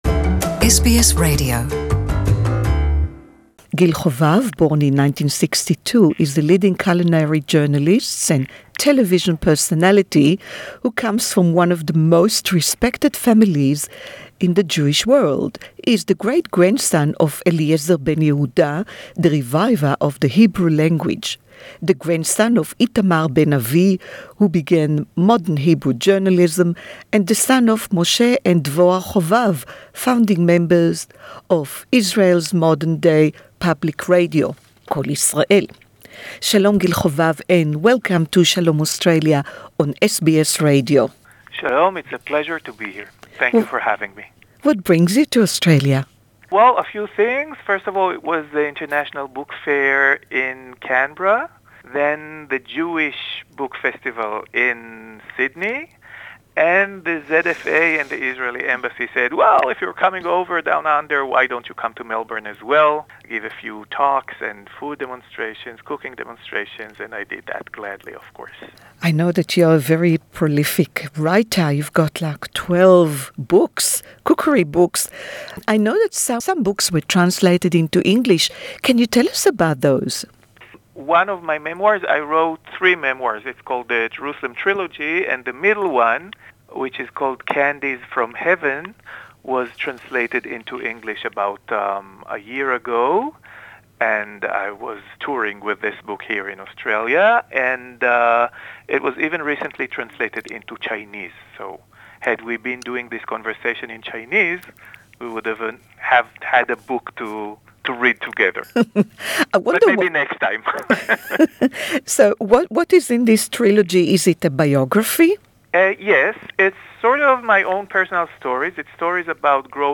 Gil Hovav (English Interview) is proud of his great grandfather that made history, Eliezer Ben Yehuda